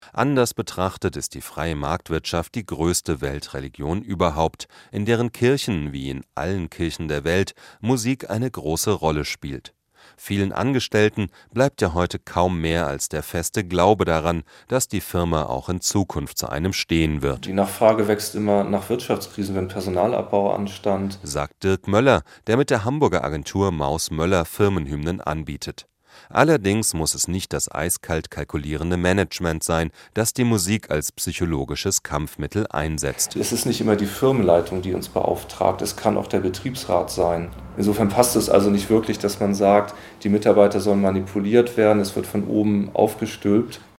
Juni 2012: Bewerben per Webcam spart Zeit und Geld Mitschnitt Deutschlandradio Wissen (DRadio Wissen), 25. April 2012: Firmenhymnen. In einem etwas salbungsvollen, düsteren und unternehmenskulturkritischen Beitrag befasst sich DRadio Wissen mit Firmenhymnen.